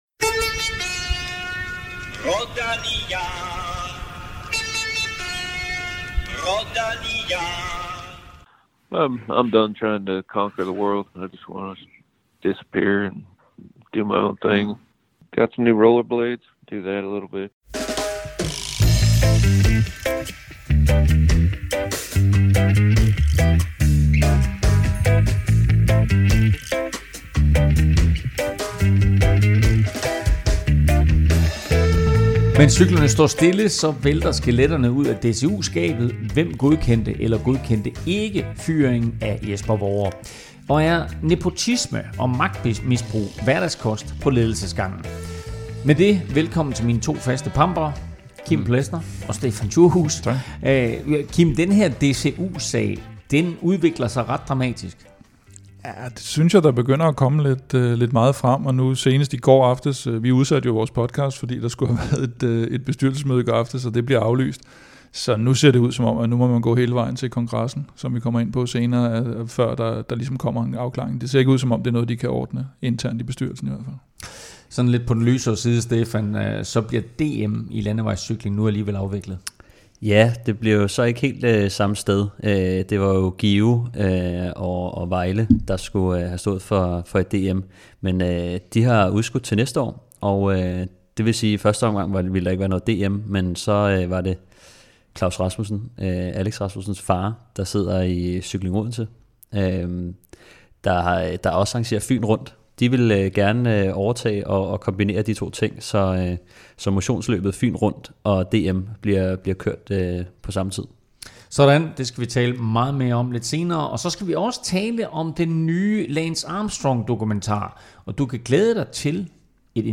Vi forsøger at give et overblik over balladen i DCU's bestyrelse og vi anmelder den nye Lance-dokumentar og diskuterer den syvdobbelte Tour-vinders relevans. Der er interview